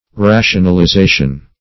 Rationalization \Ra`tion*al*i*za"tion\
(r[a^]sh`[u^]n*al*[i^]*z[=a]"sh[u^]n), n.